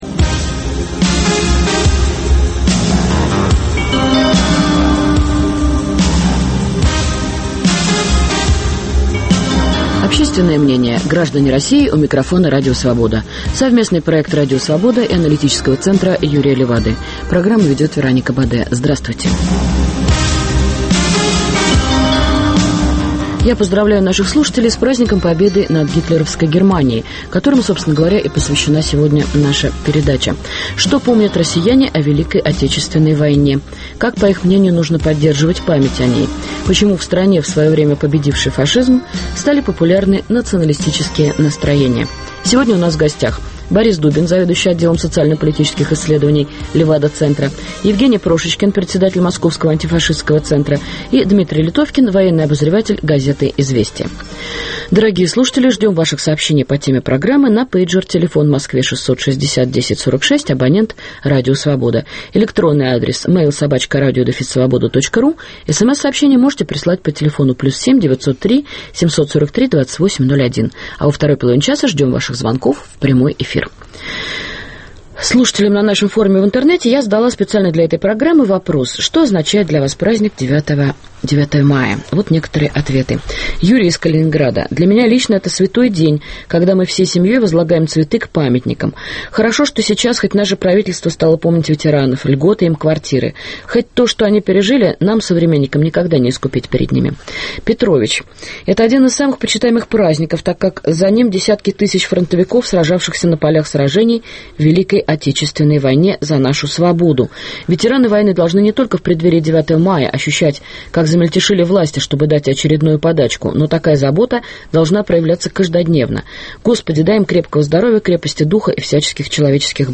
Ждем звонков в прямой эфир, начиная с часа дня.